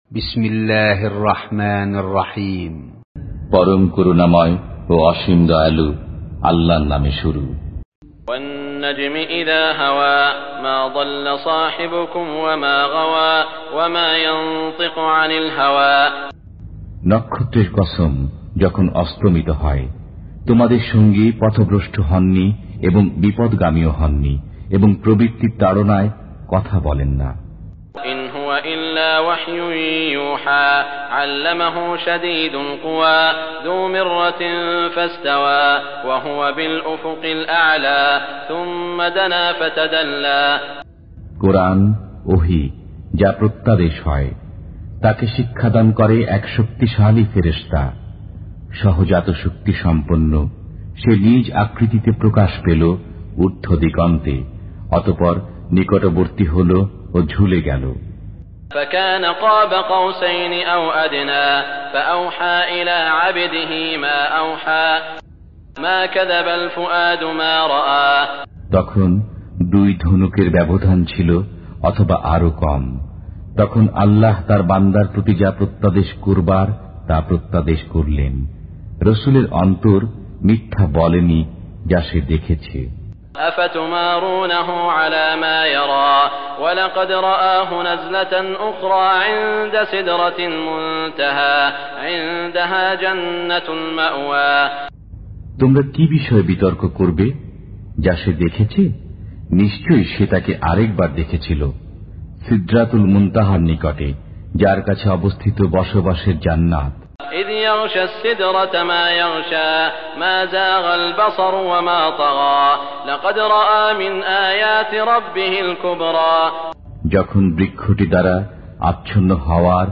কোরআন তেলাওয়াত